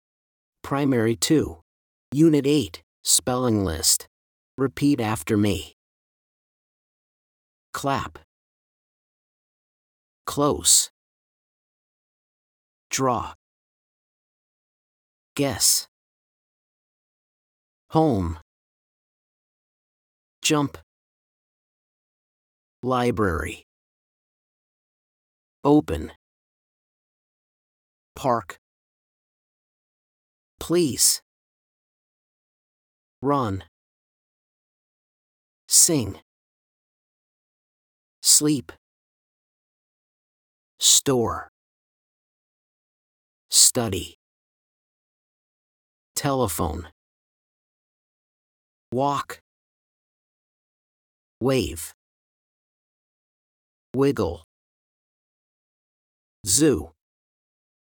SPELLING LIST FOR UNIT 8
These are the words on the spelling list. Listen and repeat after the teacher: